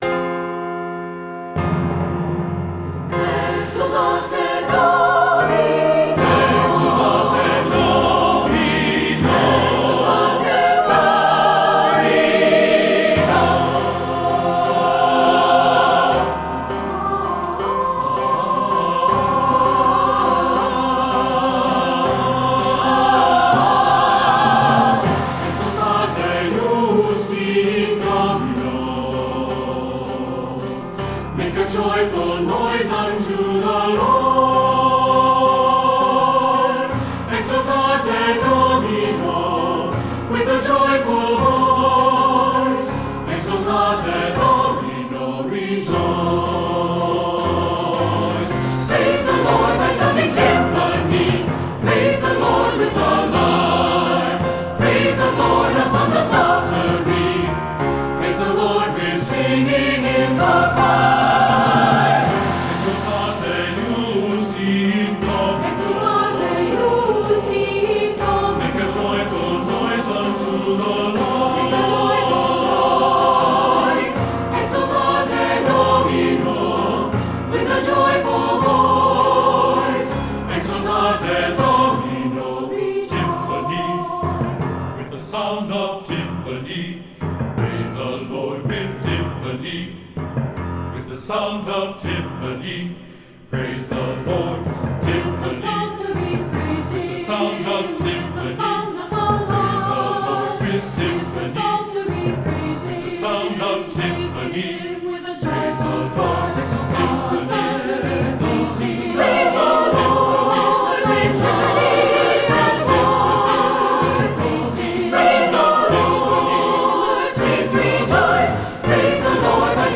instructional, secular choral
Harp part:
Chimes part:
Timpani part:
Snare drum part: